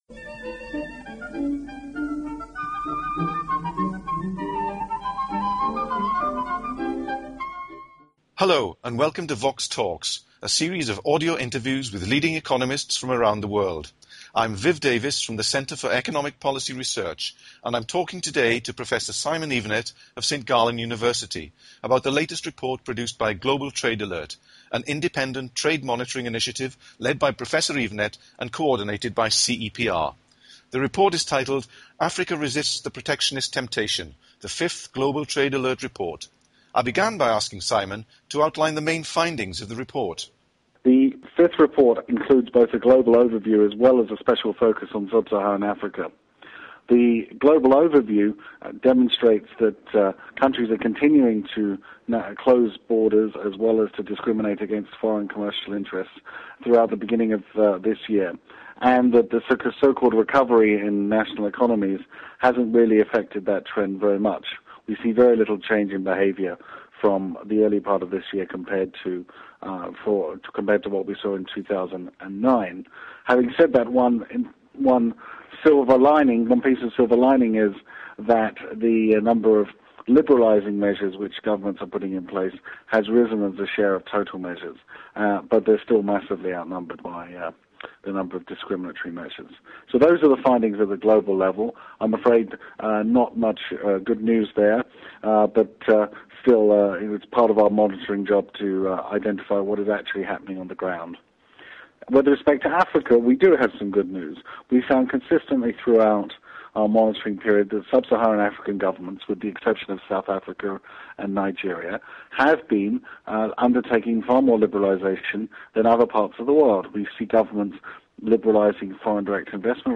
The interview was recorded in June 2010.